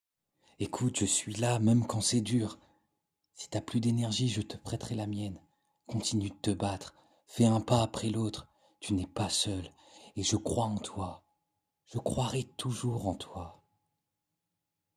Rassurant